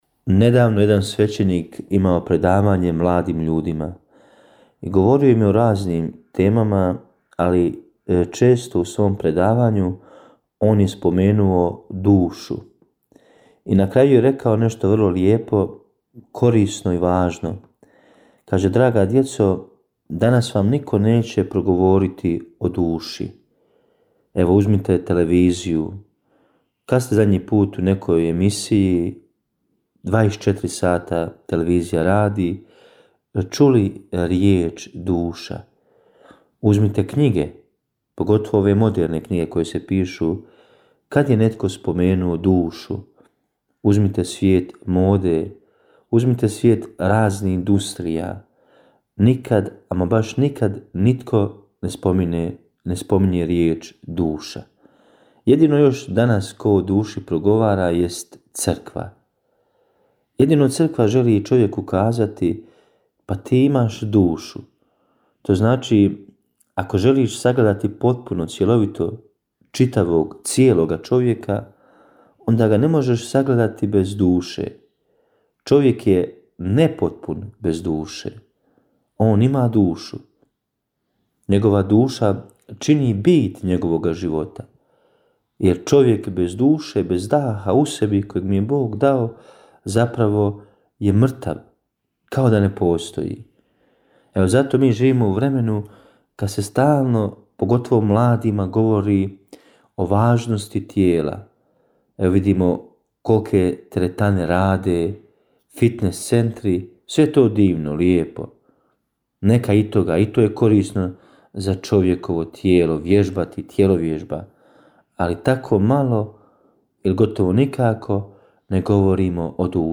Kratku emisiju ‘Duhovni poticaj – Živo vrelo’ slušatelji Radiopostaje Mir Međugorje mogu čuti od ponedjeljka do subote u 3 sata i u 7:10. Emisije priređuju svećenici i časne sestre u tjednim ciklusima.